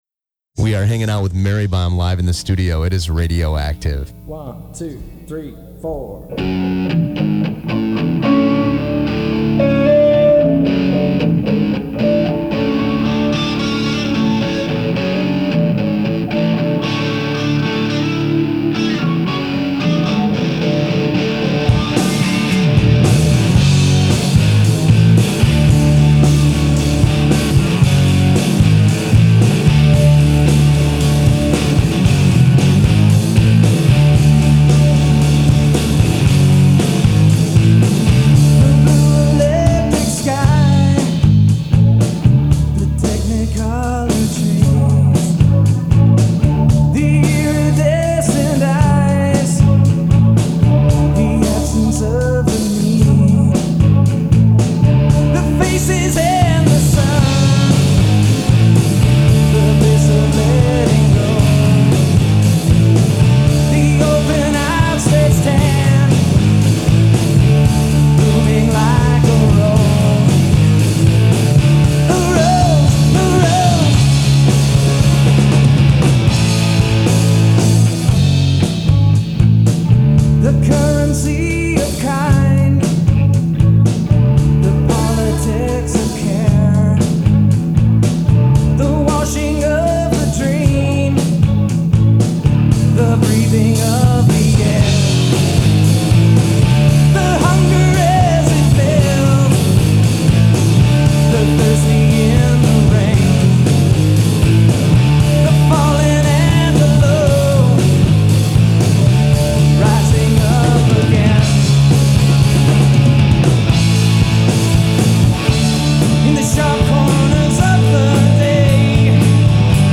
live on-air performance